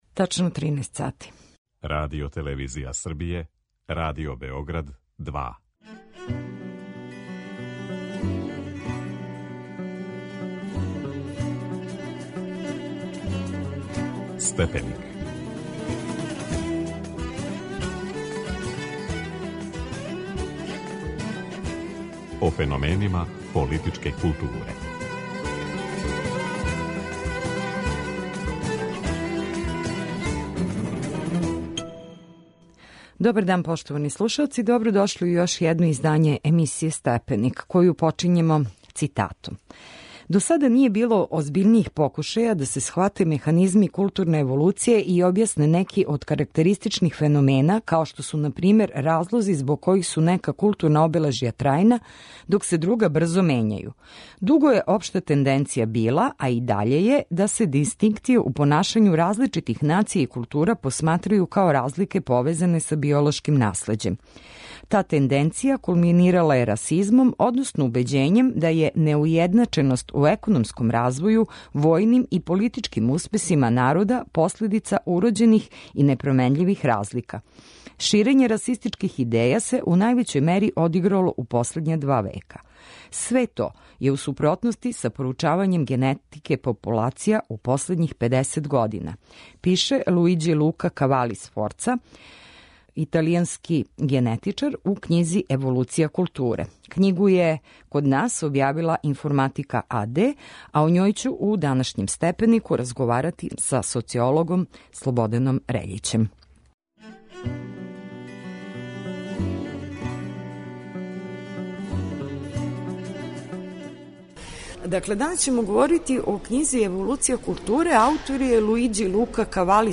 у данашњем Степенику разговарамо са социологом